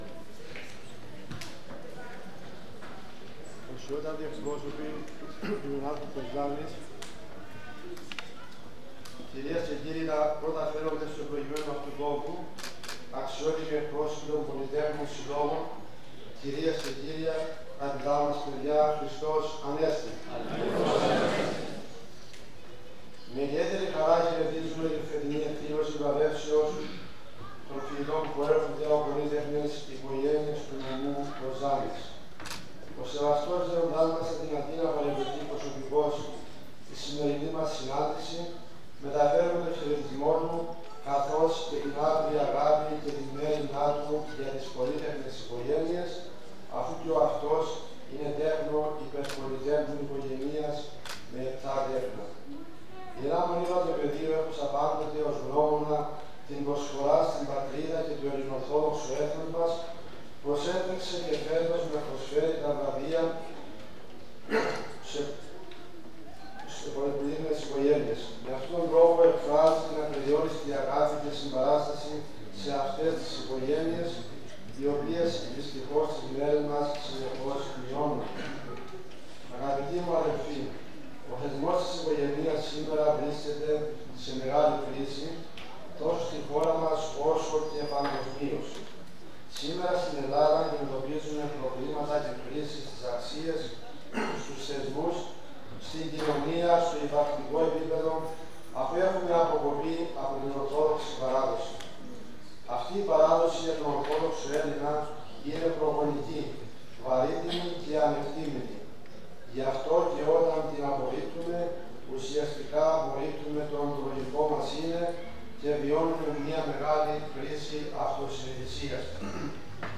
Ο Σύλλογος Πολυτέκων του Νομού Κοζάνης  πραγματοποίησε σήμερα Σάββατο, 11 Μαΐου ε.ε. στην Αίθουσα Συνεδριάσεων του Δήμου Κοζάνης, την βράβευση στις υπερπολύτεκνες οικογένειες και προς τους πολύτεκνους αριστούχους μαθητές που εισήχθησαν στην τριτοβάθμια εκπαίδευση.